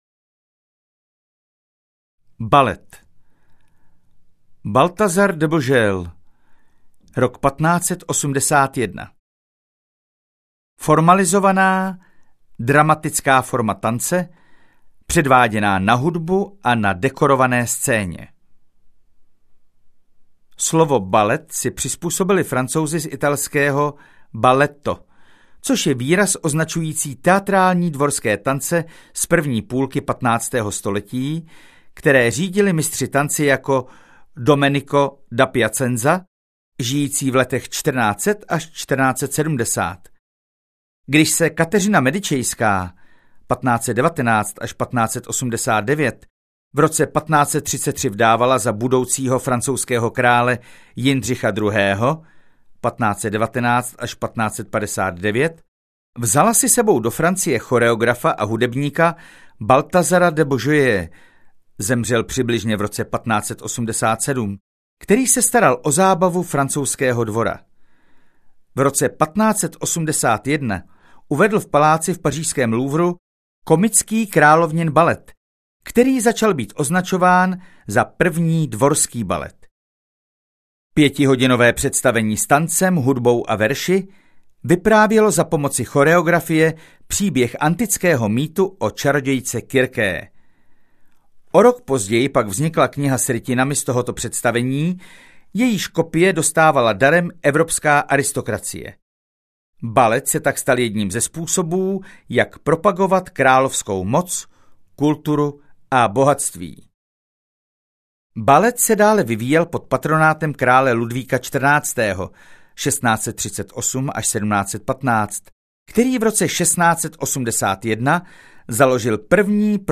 1001 myšlenek: část Umění a Architektura audiokniha
Ukázka z knihy